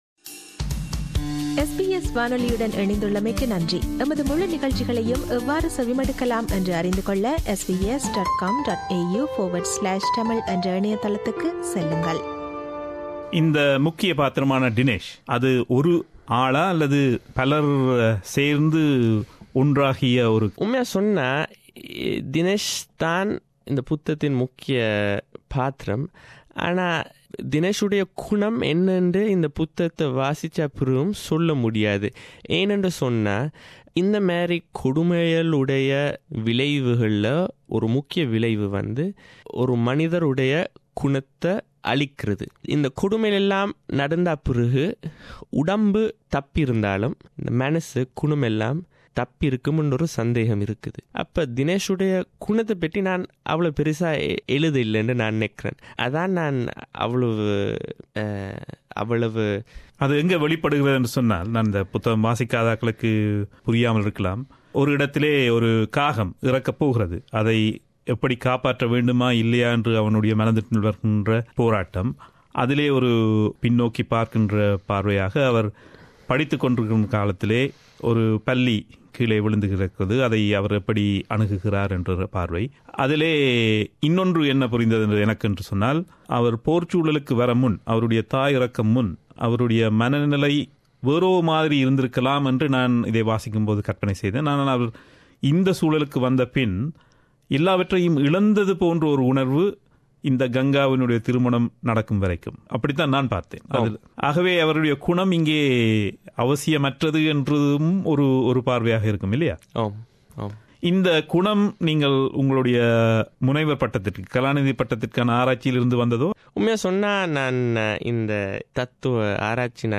நேர்காணலின் இரண்டாம் பாகம் இது.